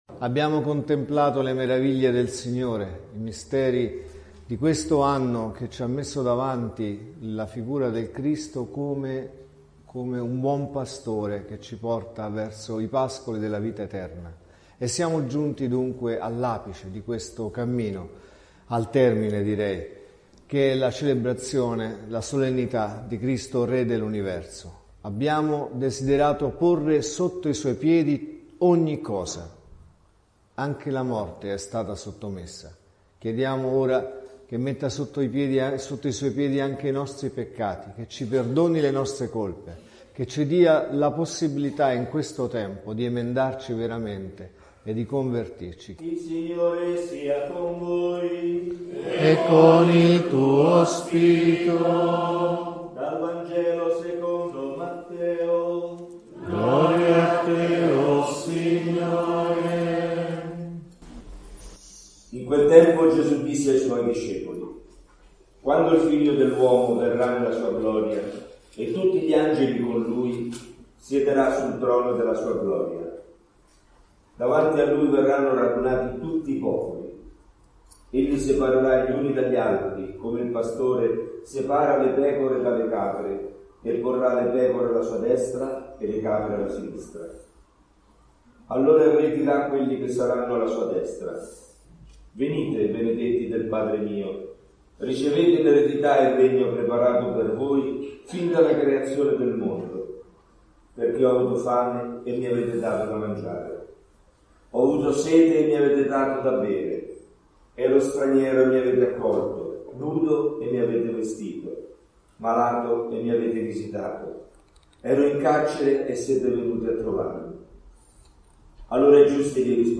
| Omelie LETTURE: Vangelo, Prima lettura e Seconda lettura Dal Vangelo secondo Matteo (Mt 25,31-46) .